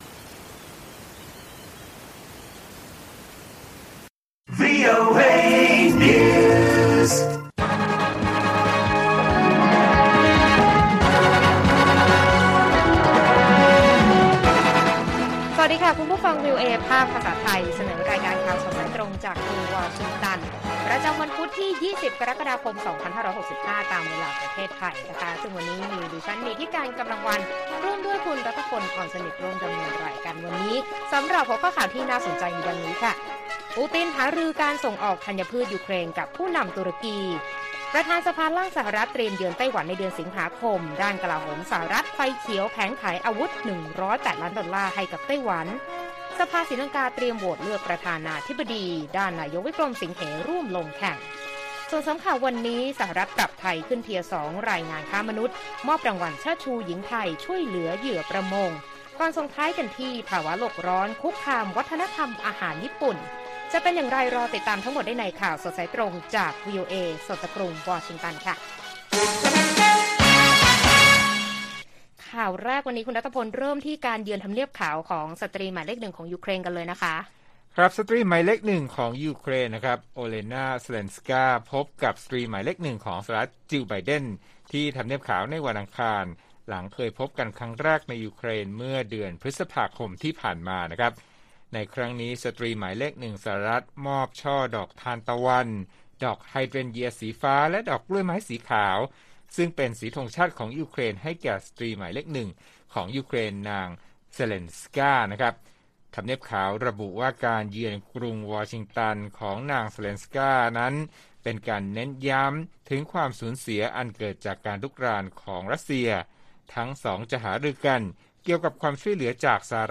ข่าวสดสายตรงจากวีโอเอ ไทย พุธ 20 กรกฎาคม 2565